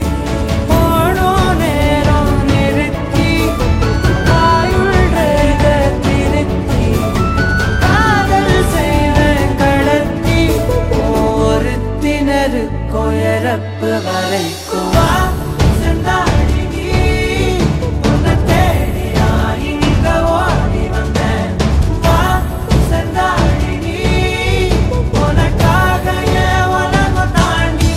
Categories: Tamil Ringtones